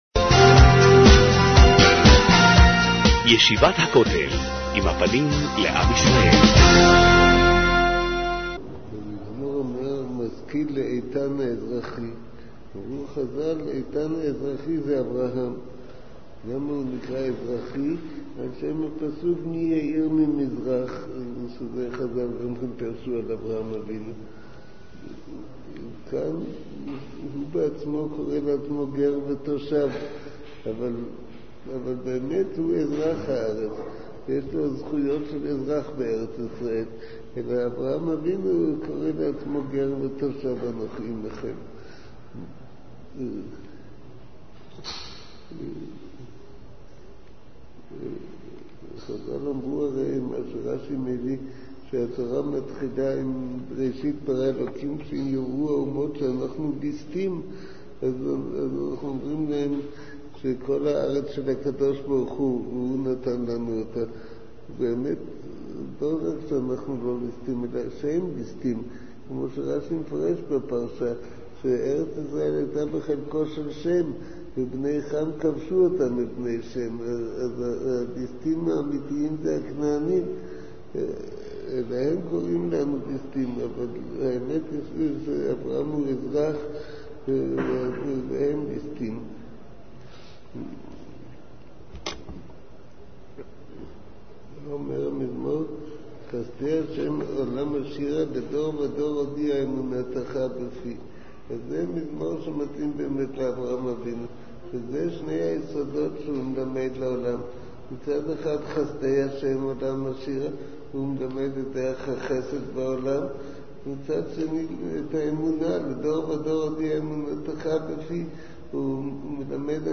מעביר השיעור: מו"ר הרב אביגדר נבנצל